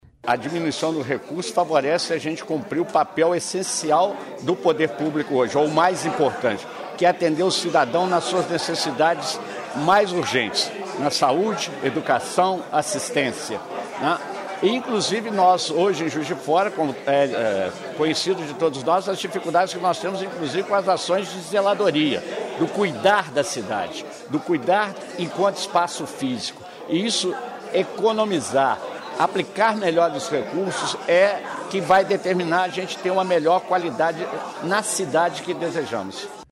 Prefeito de Juiz de Fora, Antonio Almas